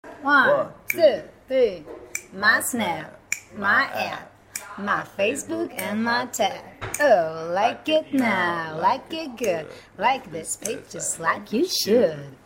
(im Chor)